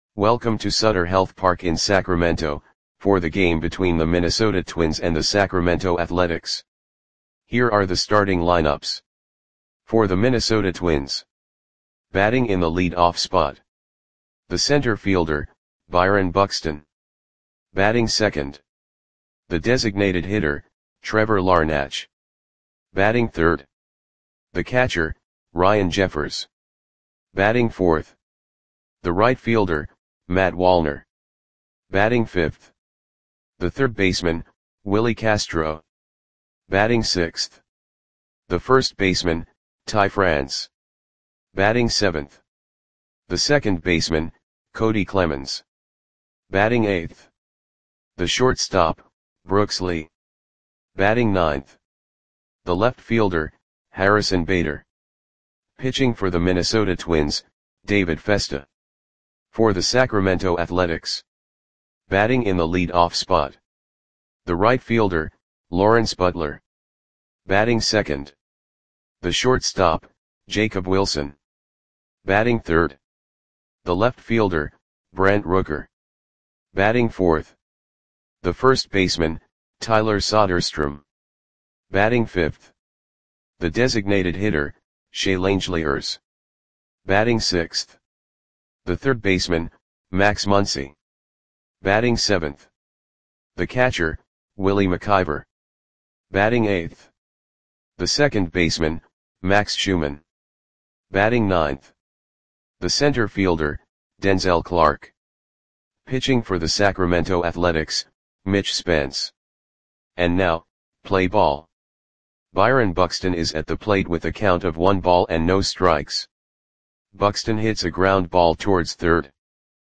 Audio Play-by-Play for Sacramento Athletics on June 5, 2025
Click the button below to listen to the audio play-by-play.